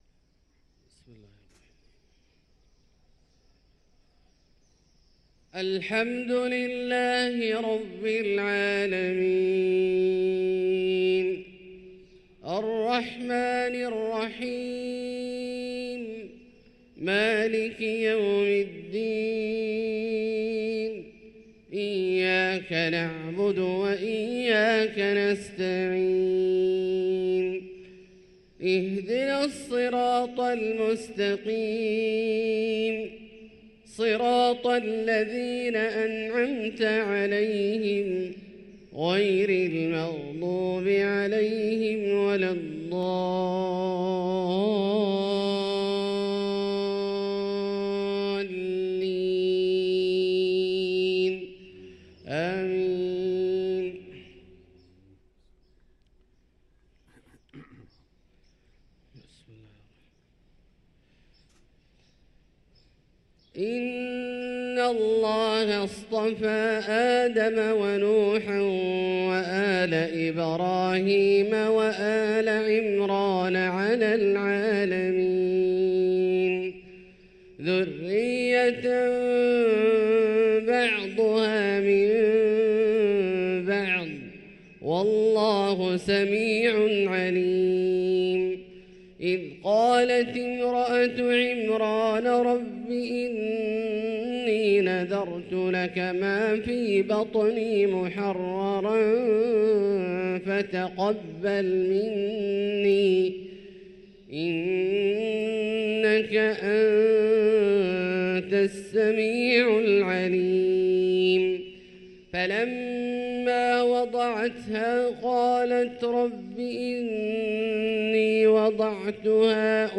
صلاة الفجر للقارئ عبدالباري الثبيتي 28 جمادي الأول 1445 هـ